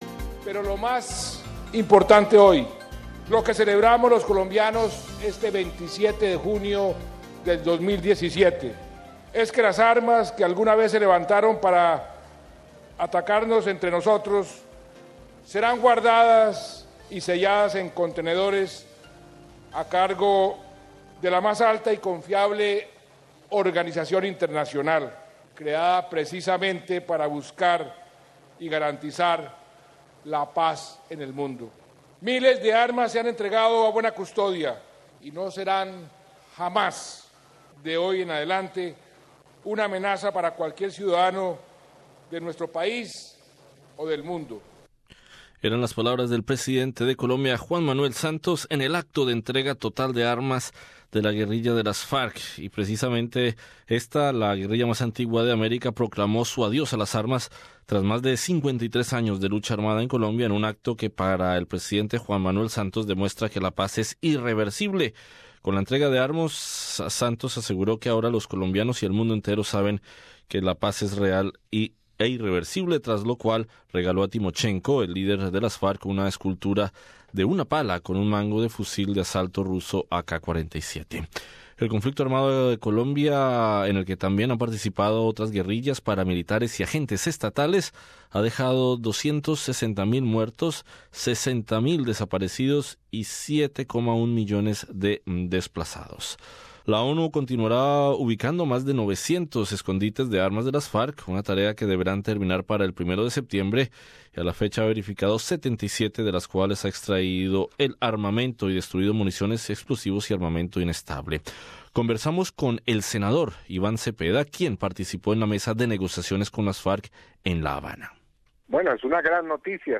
Conversamos con el senador Iván Cepeda, quien participó en la mesa de negociaciones con las FARC en la Habana.